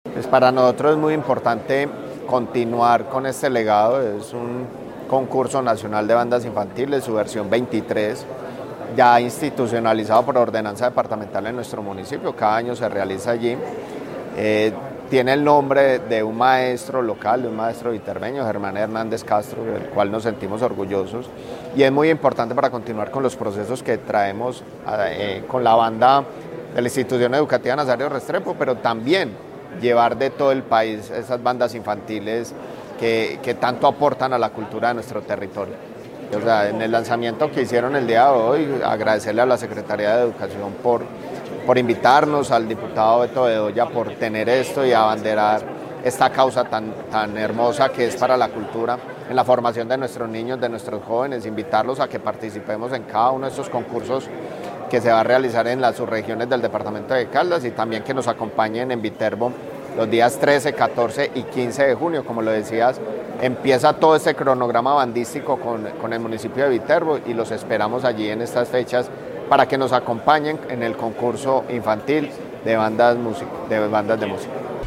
Alcalde de Viterbo, Néstor Javier Ospina Grajales.